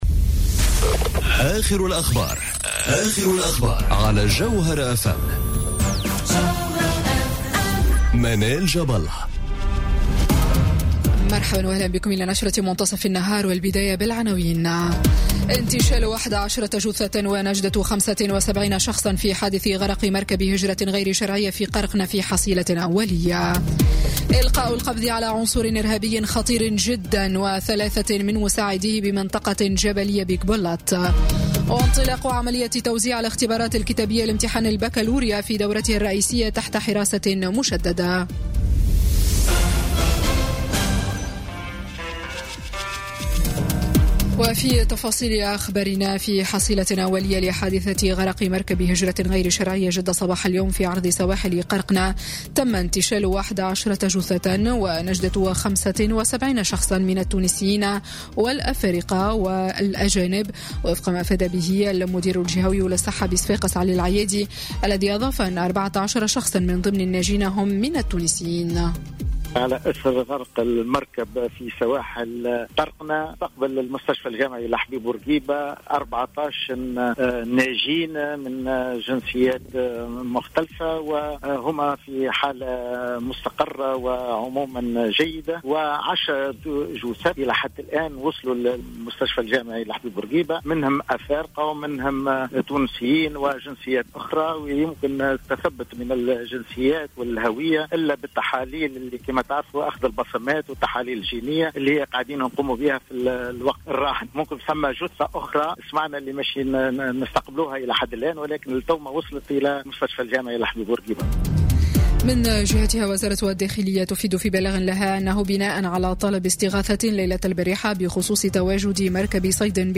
نشرة أخبار منتصف النهار ليوم الأحد 03 جوان 2018